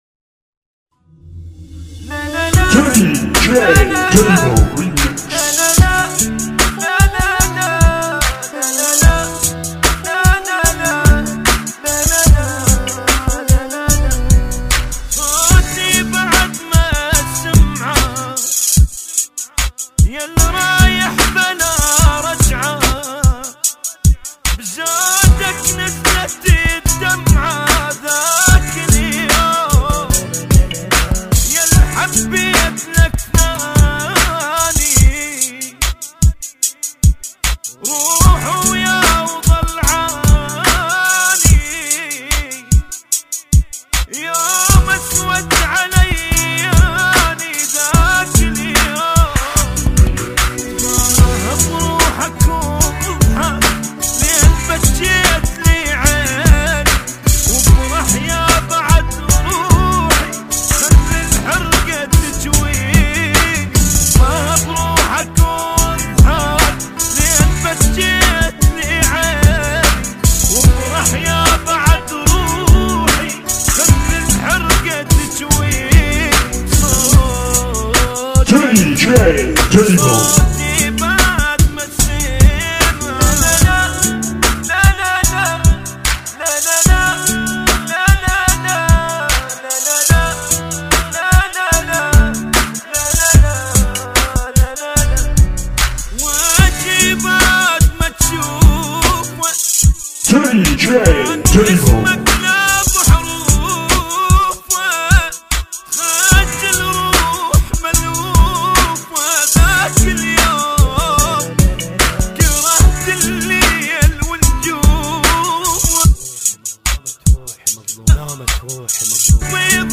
Funky MiX